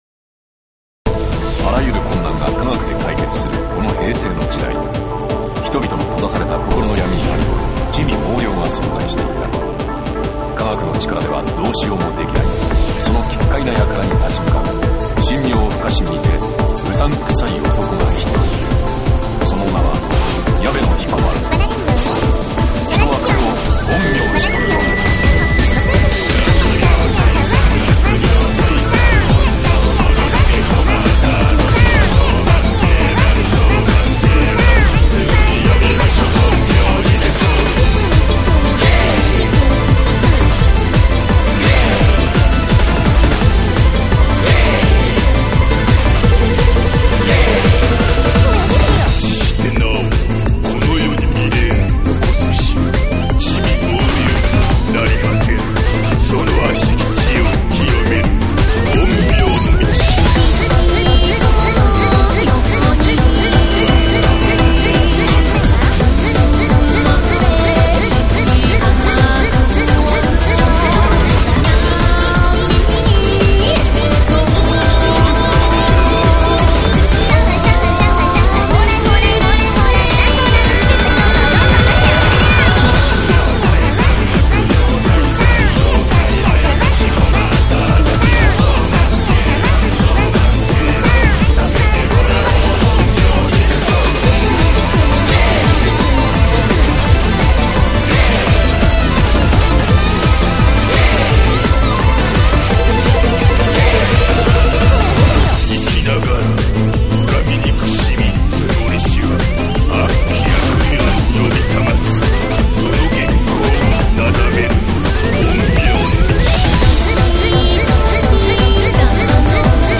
このバージョンは音が一番いいね。
タイミングか、はたまたキー調節か、理由はわからんけど不協和音がない！